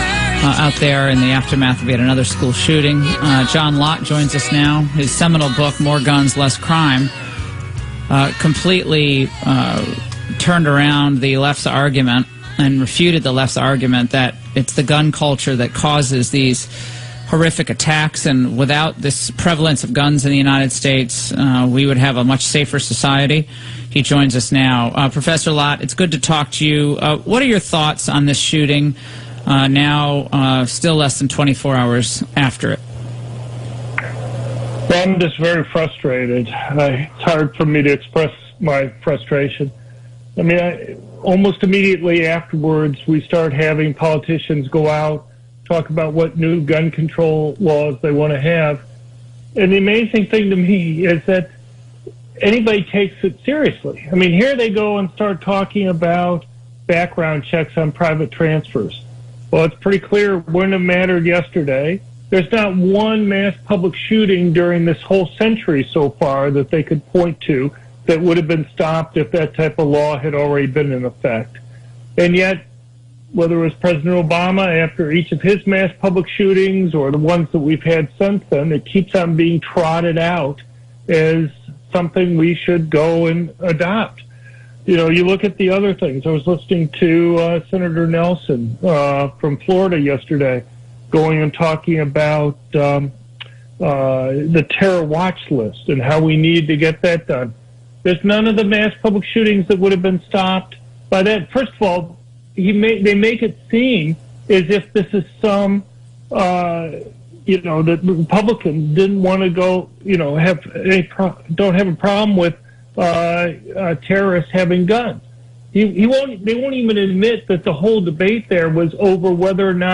Great interview: On the Laura Ingraham Radio Show to to discuss the useless policies put forward by gun control advocates and what policies might really work
media appearance
Dr. John Lott joined Laura Ingraham on her radio to discuss the useless policies put forward by gun control advocates and what policies might really work.